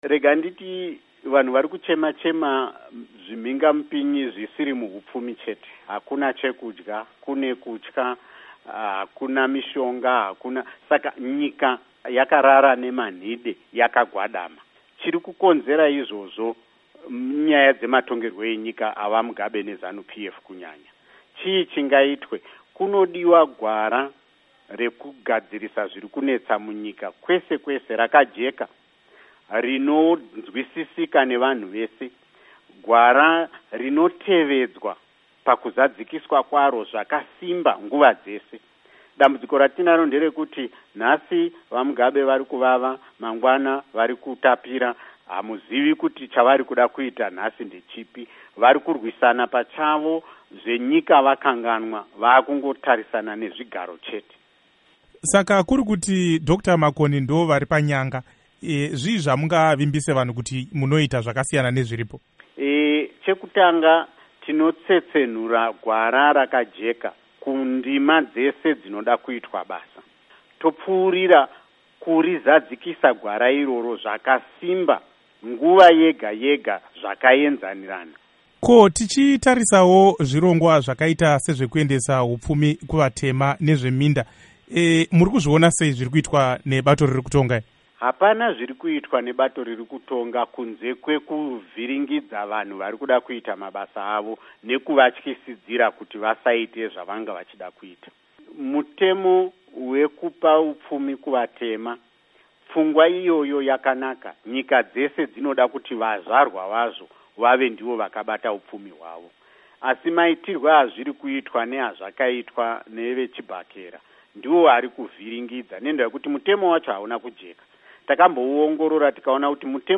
Hurukuro naVaSimba Makoni